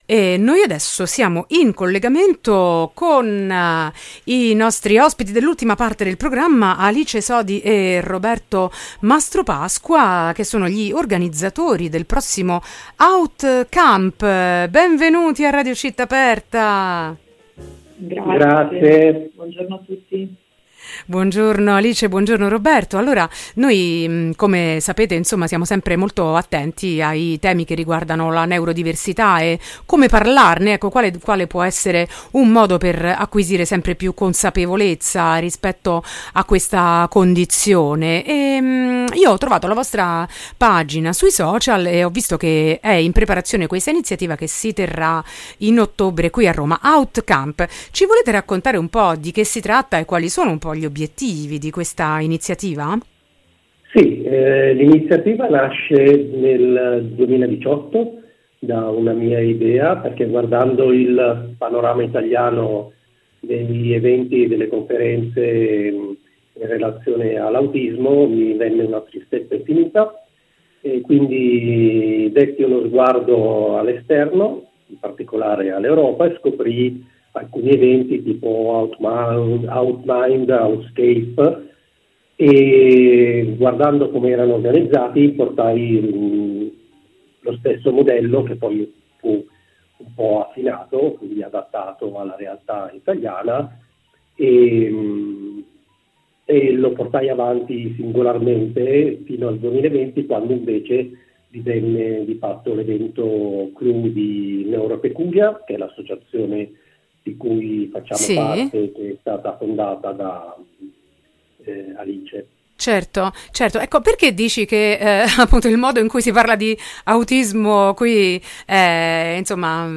Gli organizzatori presentano AUTcamp - La non-conferenza delle differenze che ha l'obiettivo di favorire il libero pensiero, la curiosità, la divulgazione e la diffusione dei temi legati al paradigma della Neurodiversità
intervista-autcamp.mp3